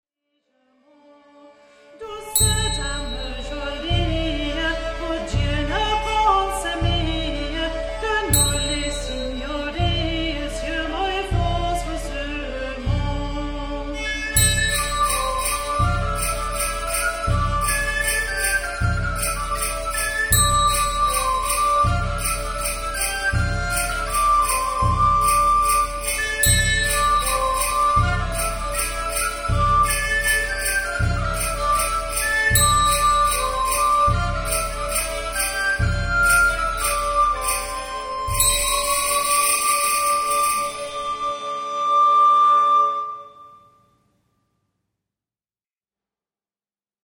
Douce Dame Jolie – Machaut. A well-known 14th century virelai by the greatest French composer of his generation, Guillaume de Machaut.  We use the symphony, recorder, rebec and percussion.